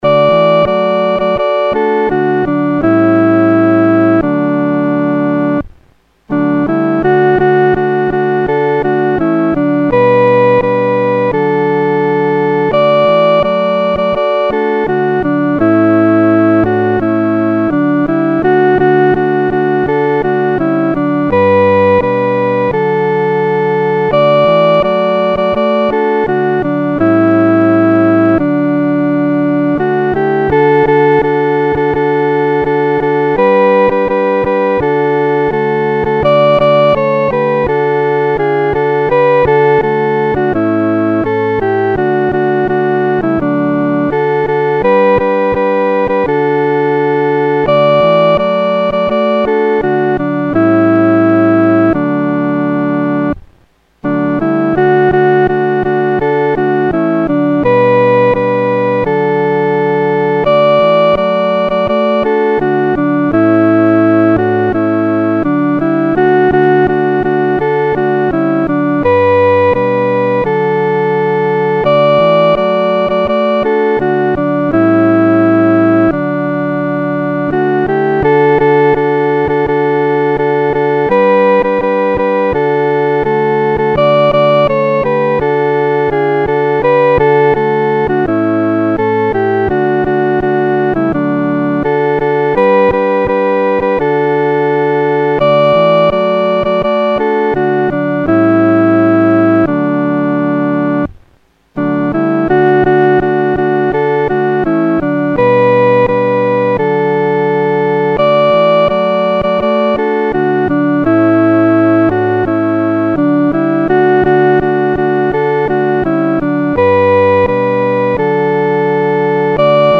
独奏（第一声）
这首赞美诗的曲调欢快，有进行曲的风味。
荣耀归神-独奏（第一声）.mp3